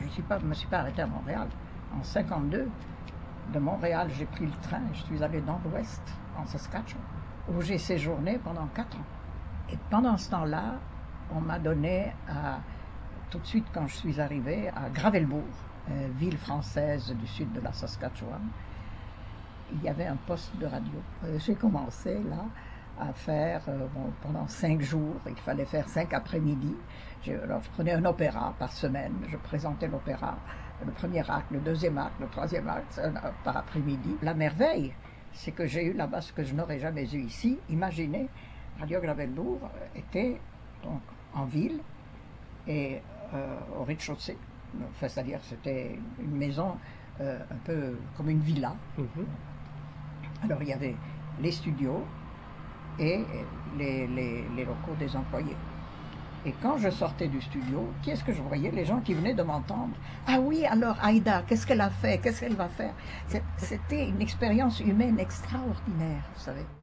L’intégrale de l’entrevue (en cassette DAT numérique) ainsi qu’un résumé détaillé et indexé dans la base de données de la Phonothèque sont disponibles pour la consultation au bureau de la Phonothèque québécoise.
Extraits des entrevues sur le patrimoine sonore :